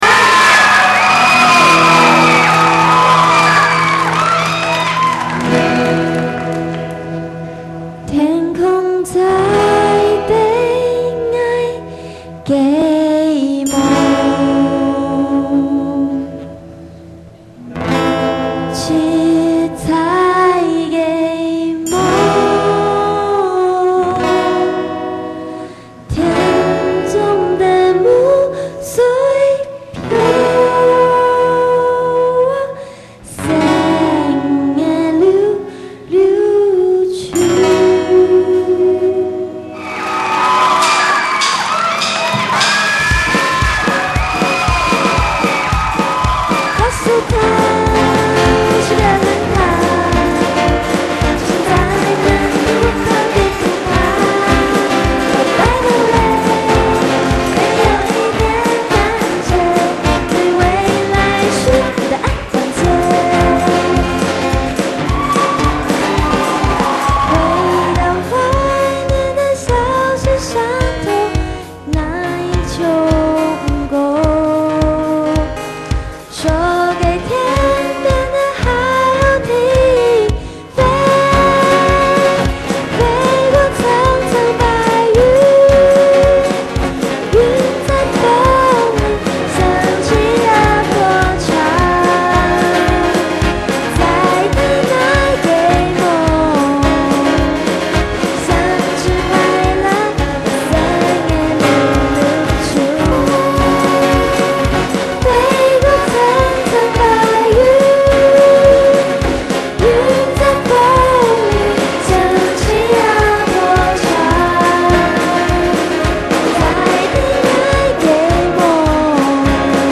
（现场版）